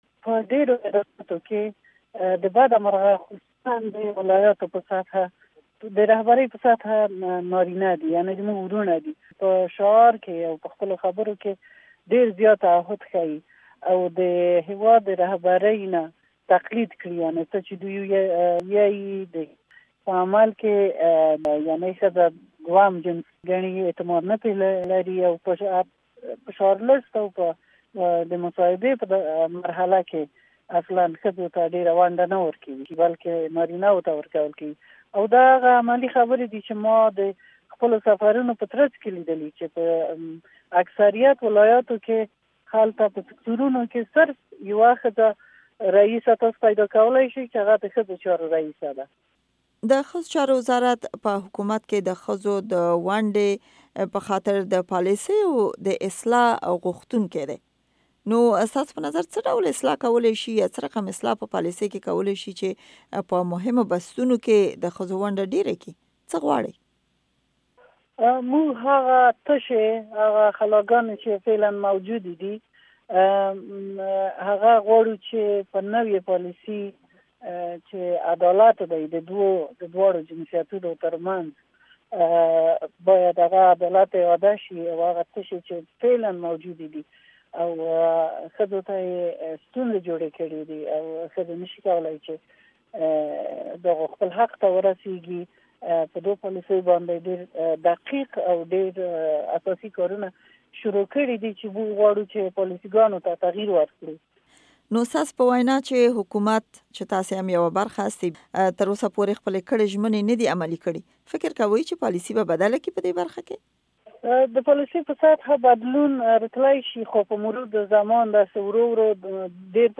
امريکا غږ سره د سپوږمۍ وردک مرکه